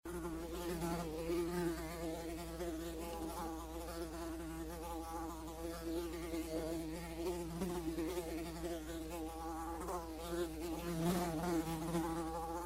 Fly Buzz-sound-HIingtone
fly-buzz.mp3